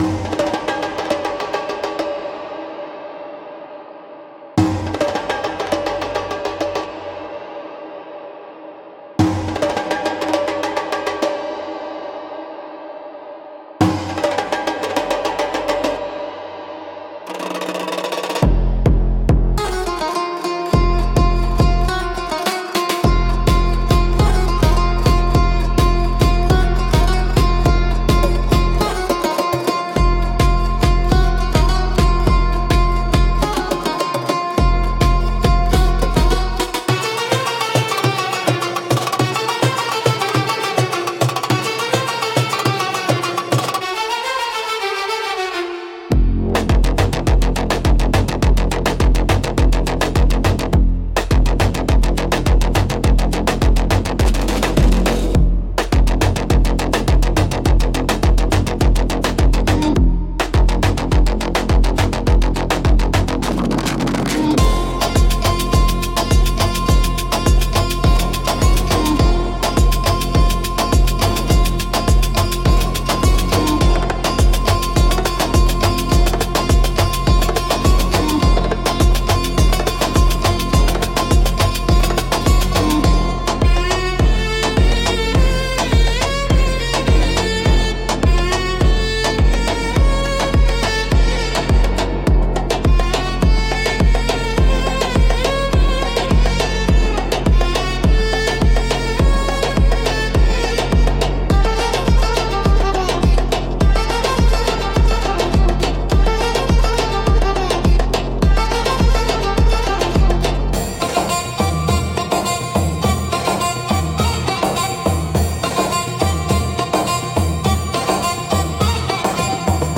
Instrumental - Blood Moon Mirage